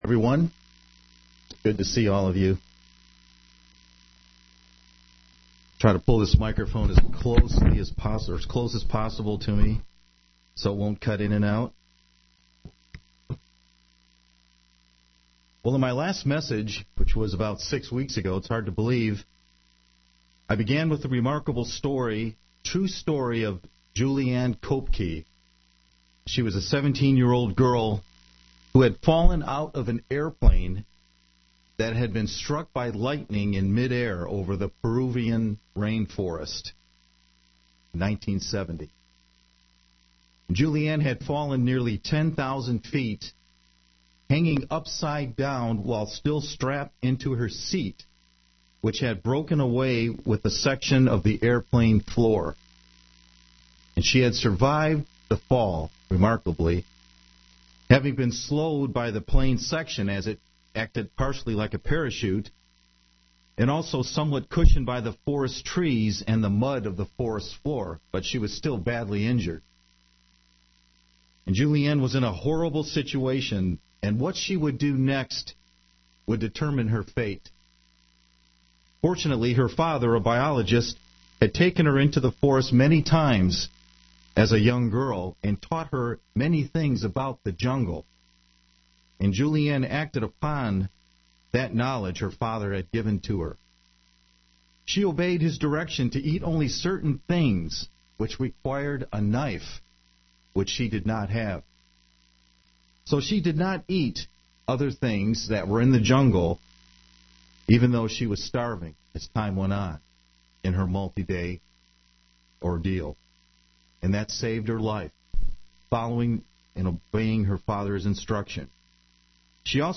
The second part of a sermon about Juliane Koepcke who survived a plane crash in 1971. We can learn from her example of survival and discover 5 spiritual lessons.